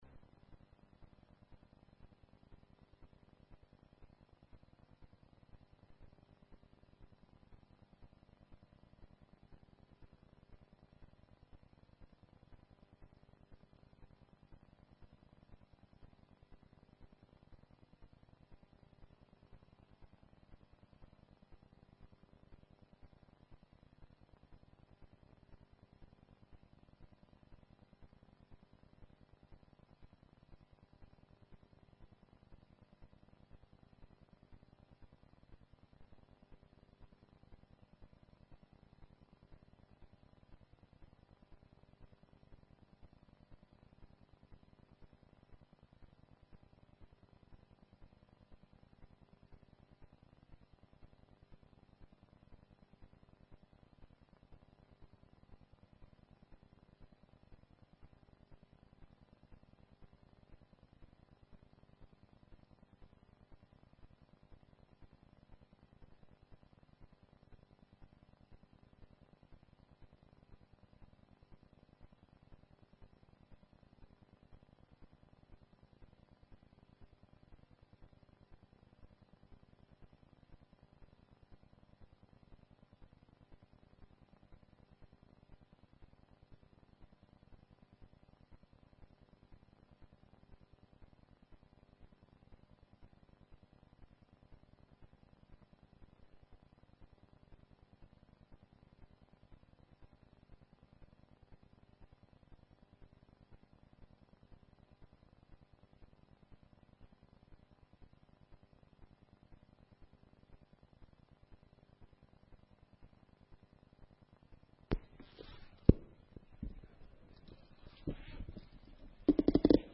وعظ و خطابه